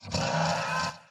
sounds / mob / horse / skeleton / idle3.mp3